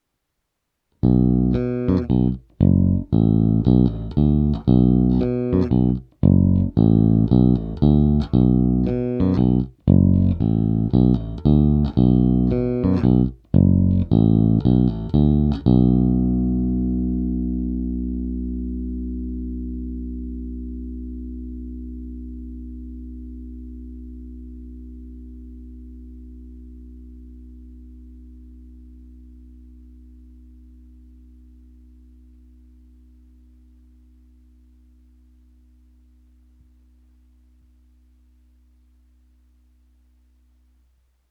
Není-li uvedeno jinak, ukázky jsou provedeny rovnou do zvukové karty a jen normalizovány. Hráno vždy nad snímačem.